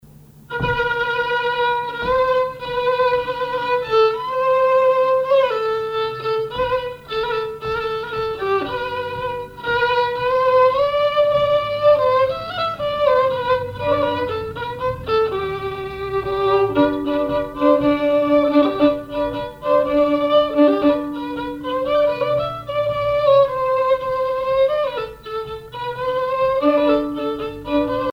violoneux, violon
instrumentaux au violon mélange de traditionnel et de variété
Pièce musicale inédite